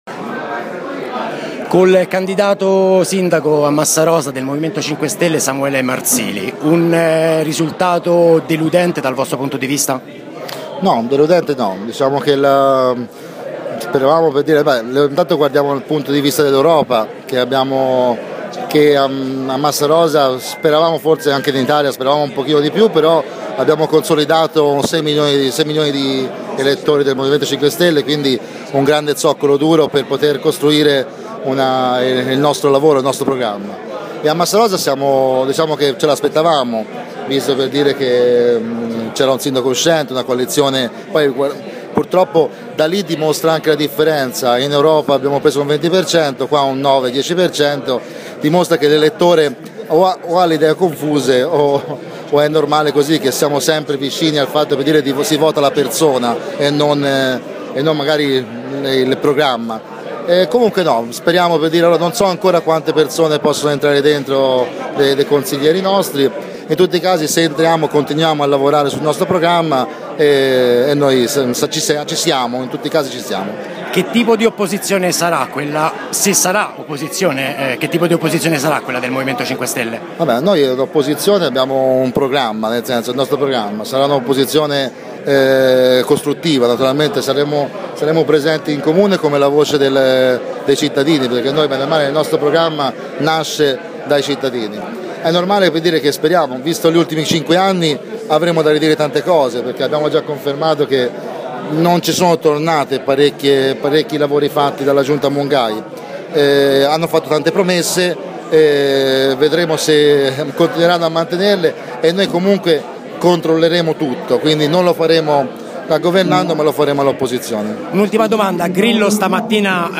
Di seguito l’intera audio intervista